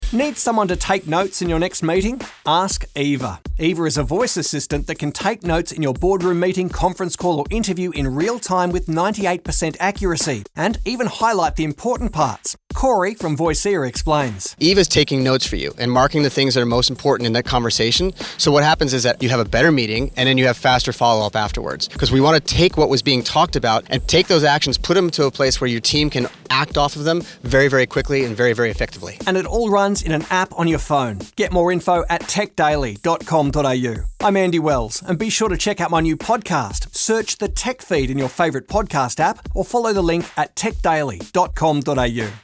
Digital voice.